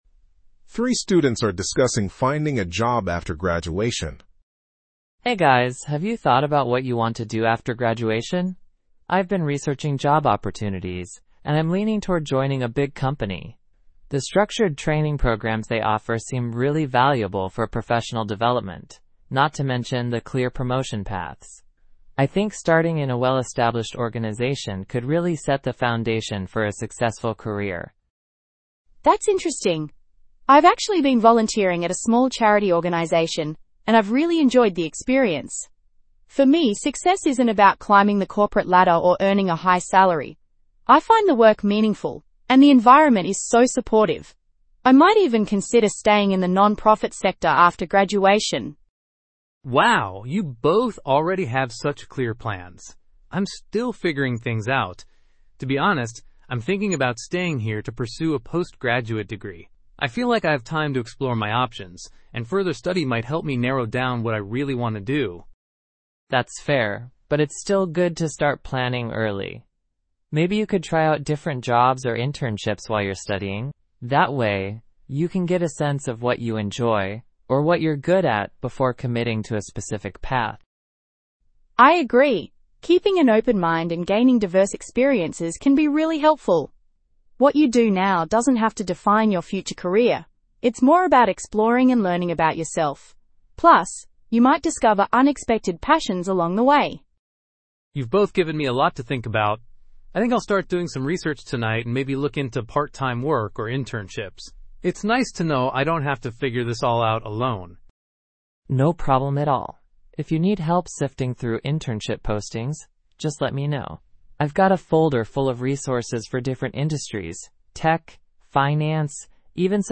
PTE Summarize Group Discussion – Future Job